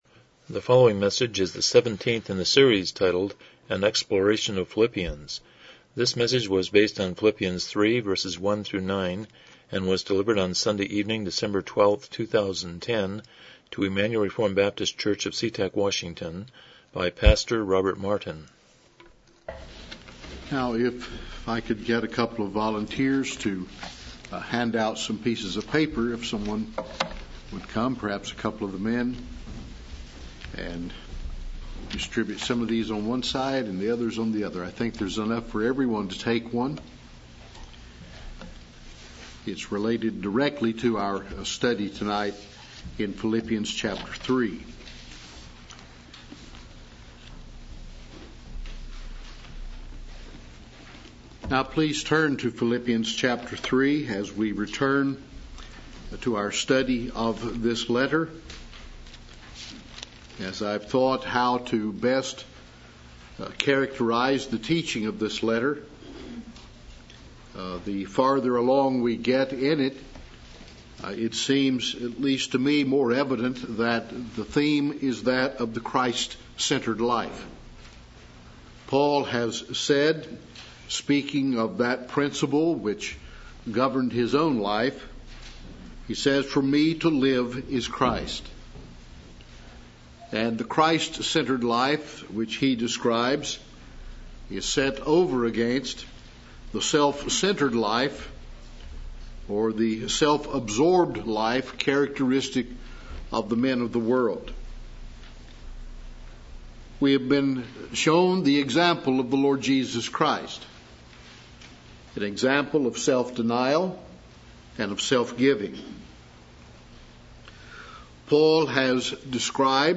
Philippians 3:1-11 Service Type: Evening Worship « 126 Romans 9:30-10:4 116 Chapter 22.7